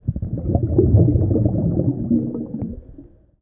Water_Breath_01.wav